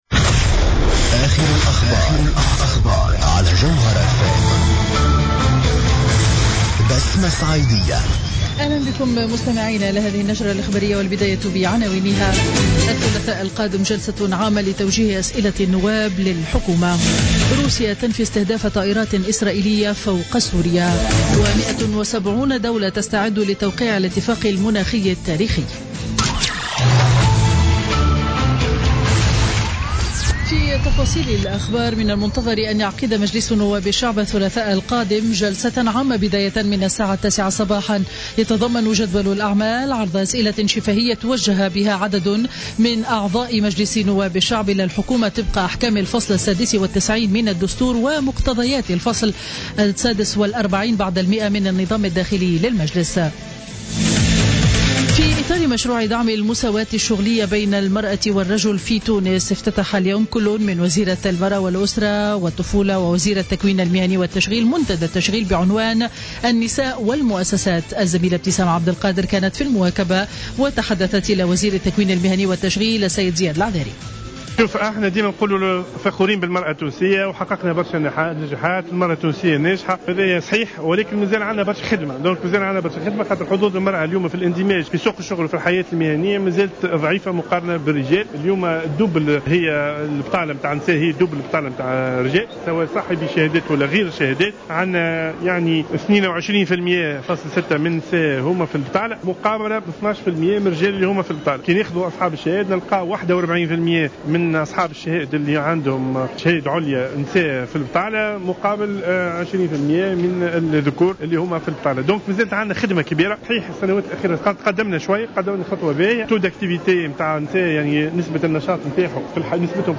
نشرة أخبار منتصف النهار ليوم الجمعة 22 أفريل 2016